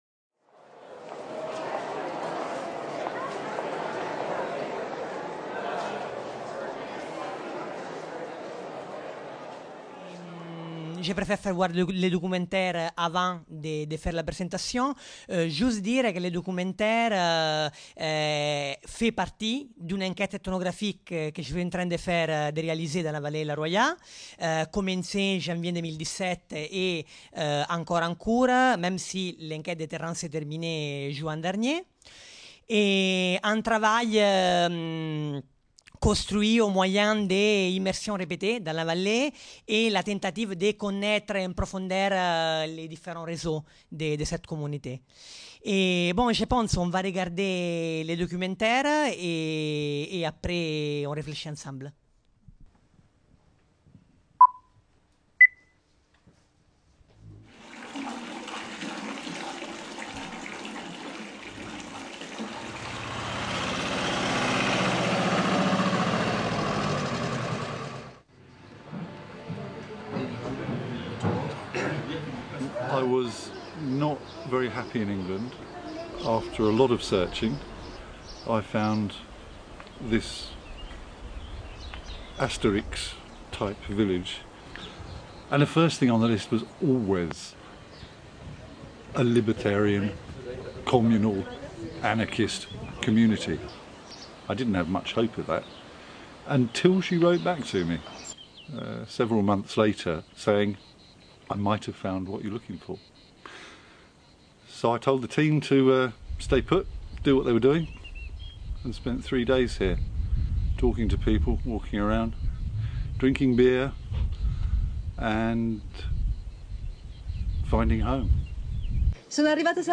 Journée d’étude organisée par l’Urmis et l’Observatoire des Migrations dans les Alpes-Maritimes à la MSHS de Nice, le jeudi 6 décembre 2018.